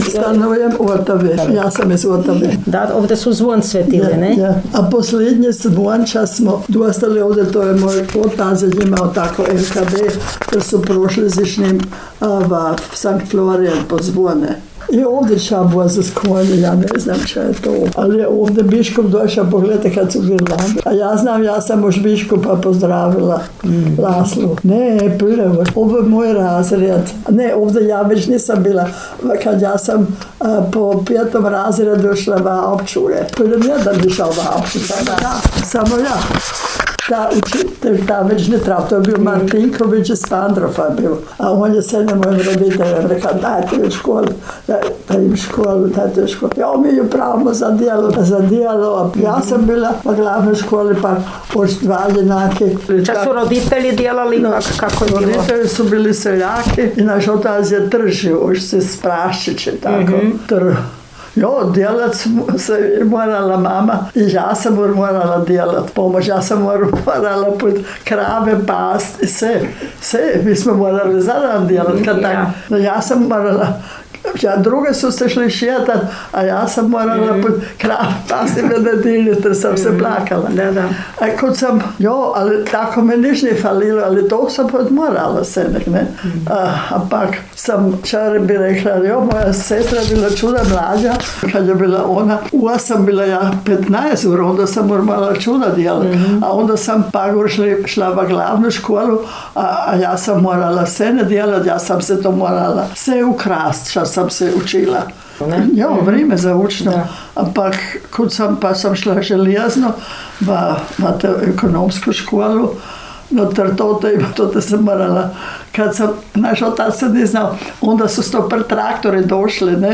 jezik naš, jezik naš gh dijalekti
Otava – Govor – Djelo doma i školovanje
Otava_govor.mp3